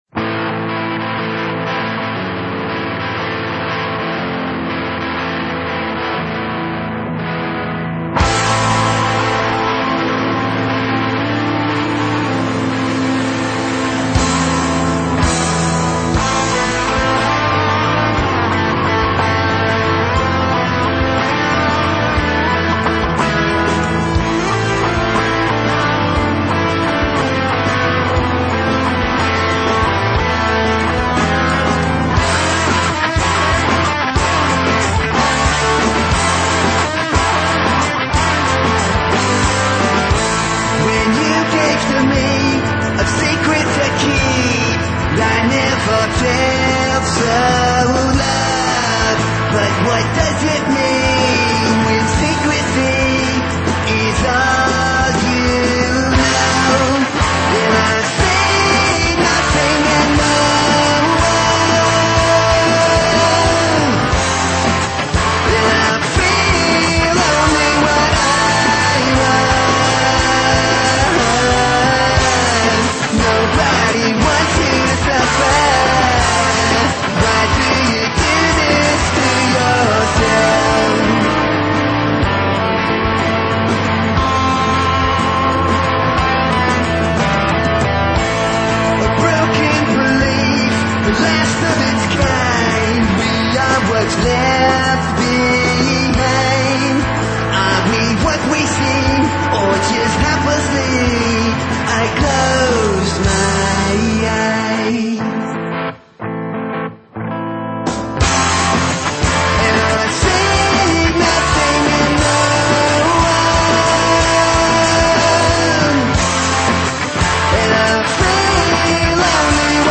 punk
rock
metal
high energy rock and roll